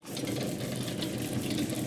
GleiseLoop.wav